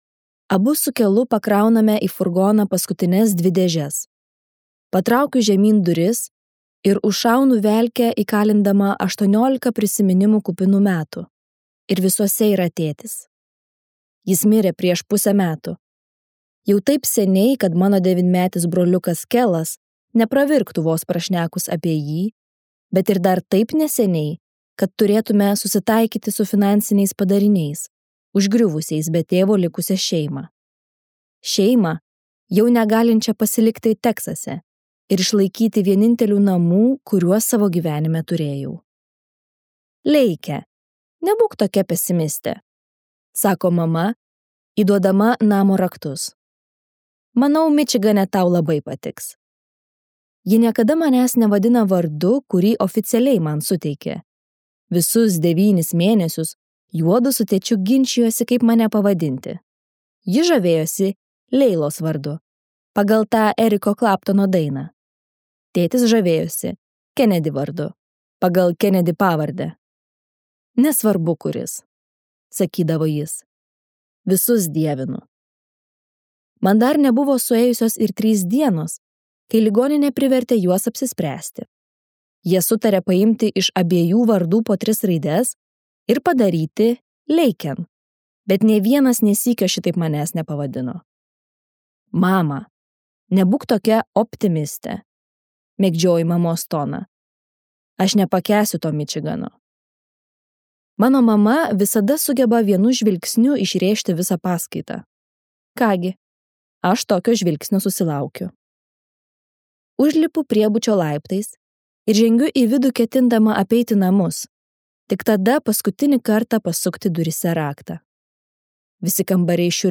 Slemas | Audioknygos | baltos lankos